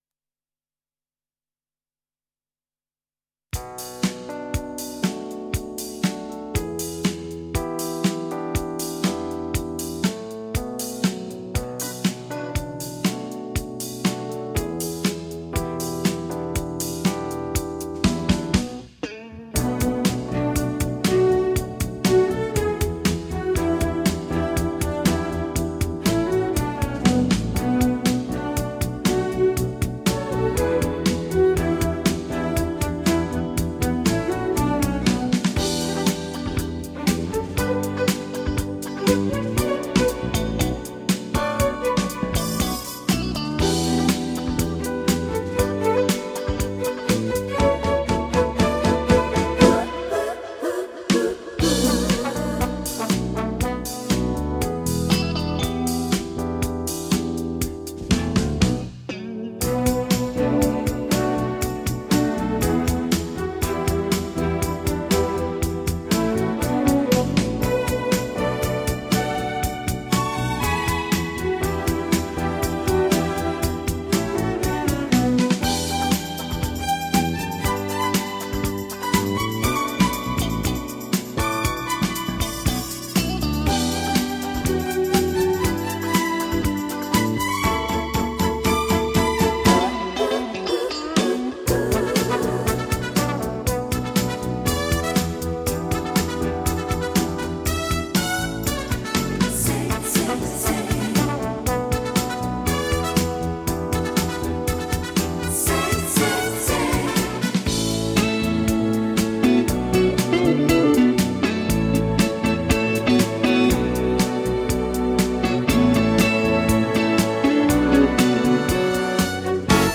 人类音乐史上最优美的轻音乐全集 大师示范级经典作品